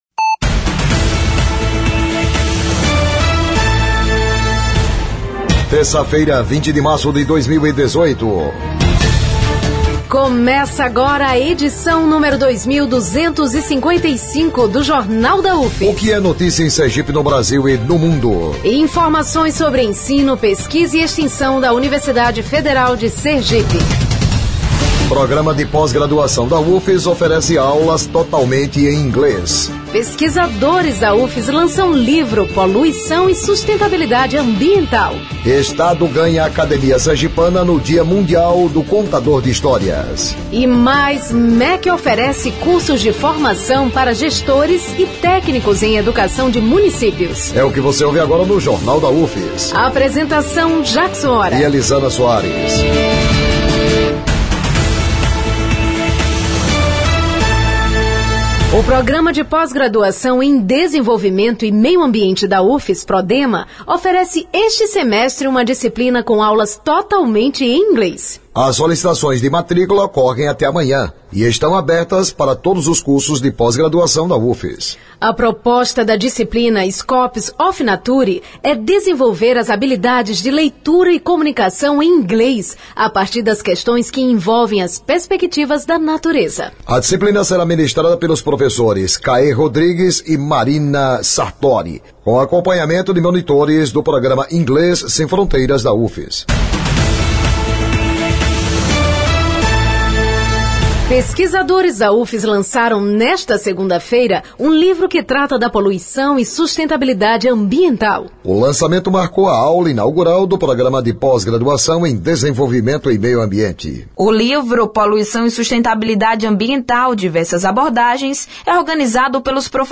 O Jornal da UFS desta terça-feira, 20, repercute o lançamento do livro: Poluição e Sustentabilidade Ambiental: Diversas Abordagens. A obra reúne publicações de 26 pesquisadores do Programa de Pós-graduação em Desenvolvimento e Meio Ambiente da UFS. O noticiário vai ao ar às 11h na Rádio UFS FM, com reprises às 17h e 22h.